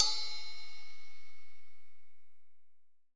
Index of /90_sSampleCDs/AKAI S6000 CD-ROM - Volume 3/Hi-Hat/AMBIENCE_HI_HAT_2